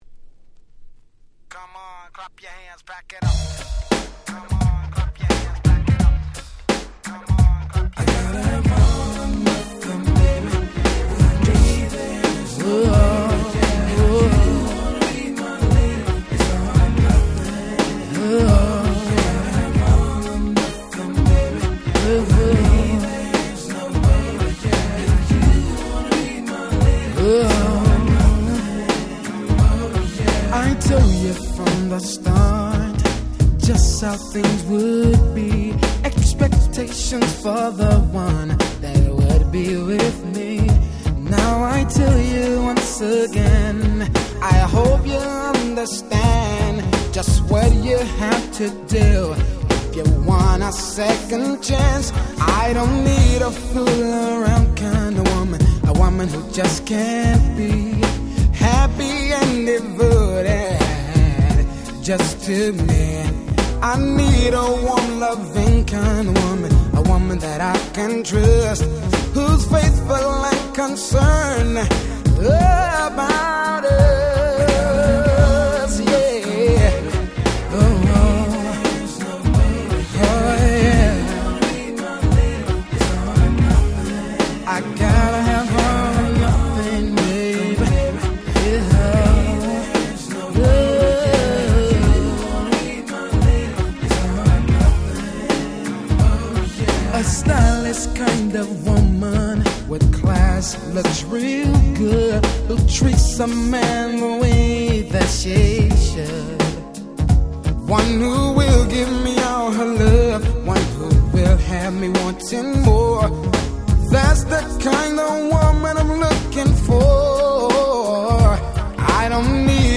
94' Very Nice R&B !!